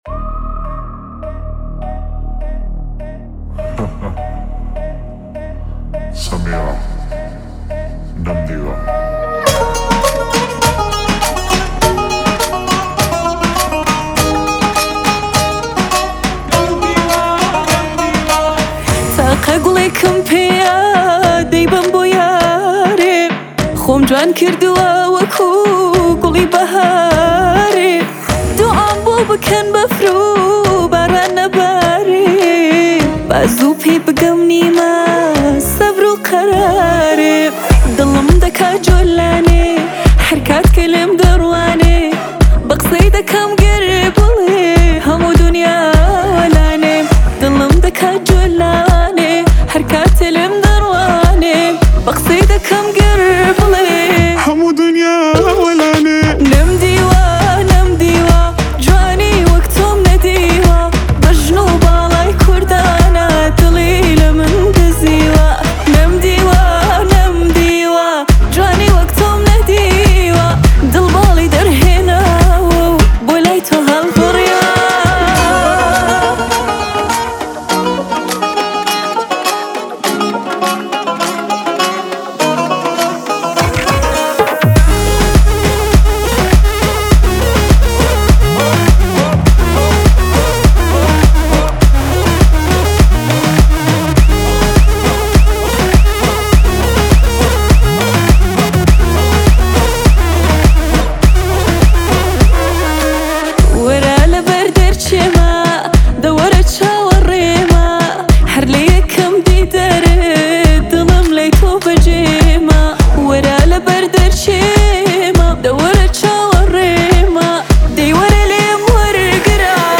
1,095 بازدید ۱۰ اسفند ۱۴۰۲ آهنگ , آهنگ کردی